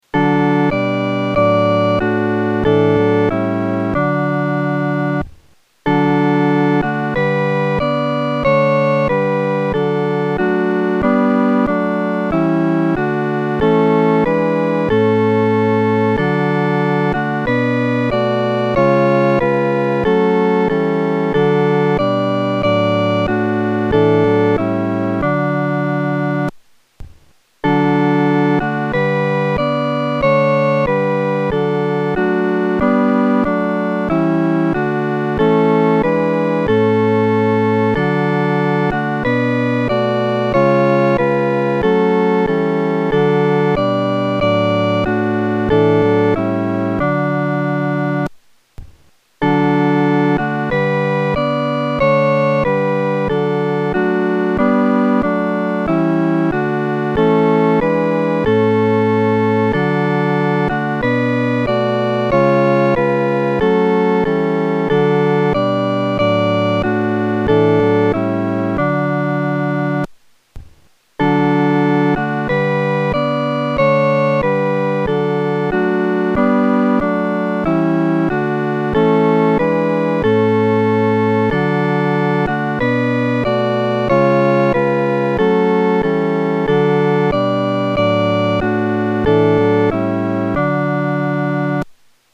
四声伴奏